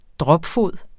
Udtale [ ˈdʁʌb- ]